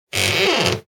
door.wav